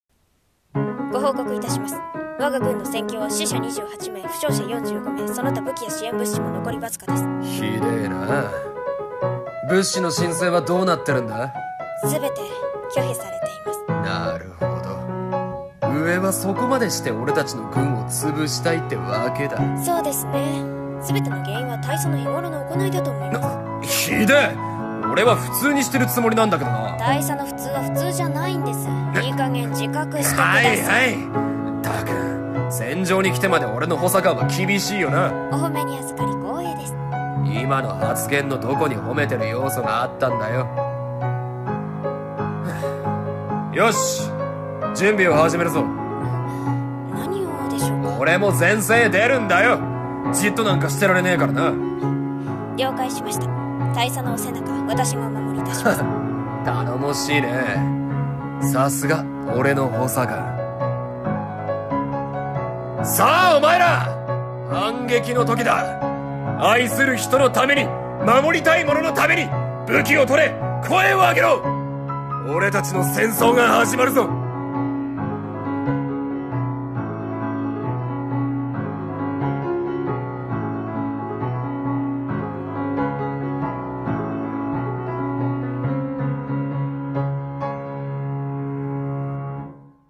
声劇台本